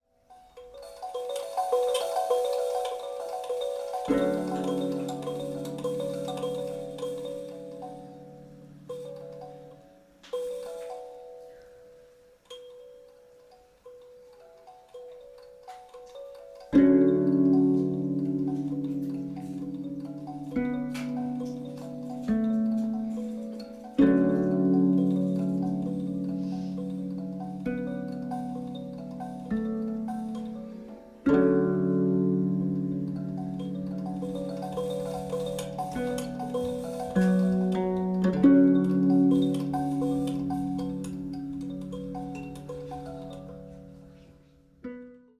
十七絃箏と、カリンバ／ハーモニウム／女声による音の綴り。
中低音の響きに豊かな印象を受ける十七絃箏が持つシンプルさと奥深さ。”
(17-strings koto)
kalimba